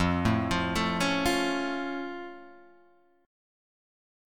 F+ chord {1 0 3 2 2 1} chord